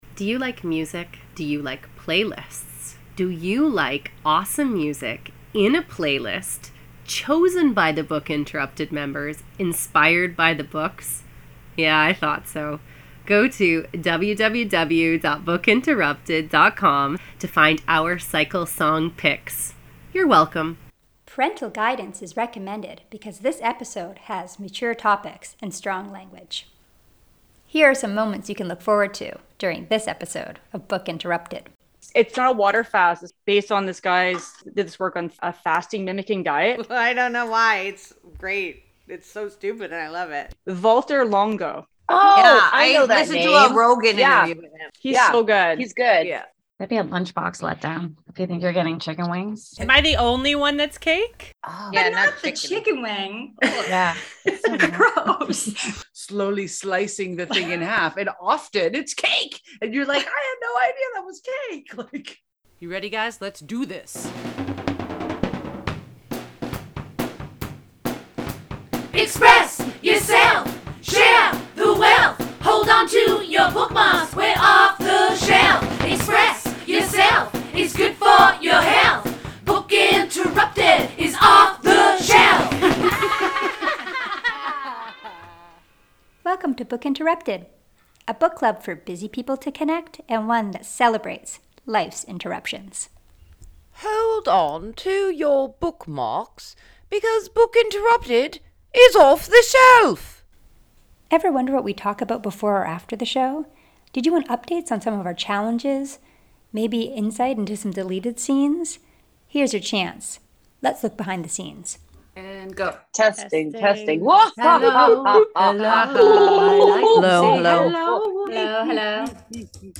During this episode we take a glimpse behind the scenes while the Book Interrupted women discuss fasting, chicken wings and is it cake?